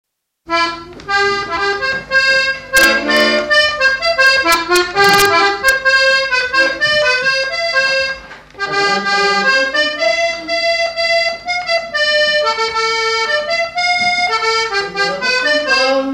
Note polka
danse : polka
Pièce musicale inédite